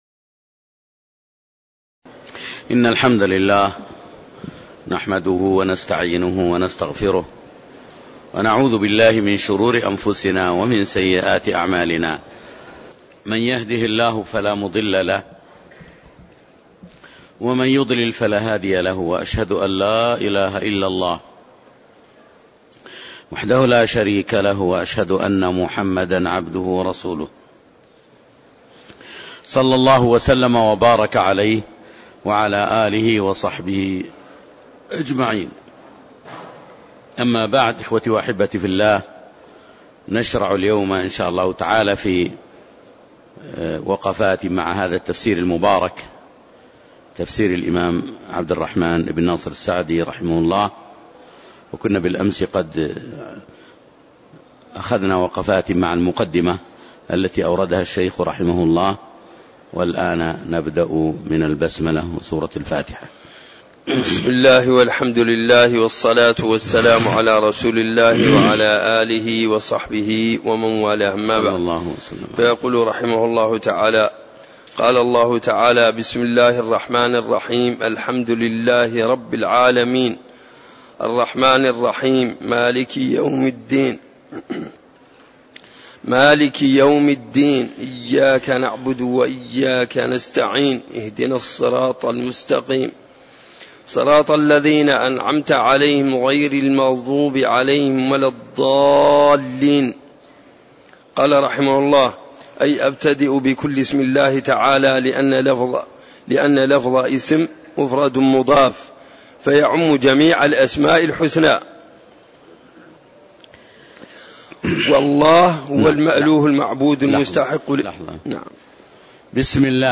تاريخ النشر ٢ رجب ١٤٣٧ هـ المكان: المسجد النبوي الشيخ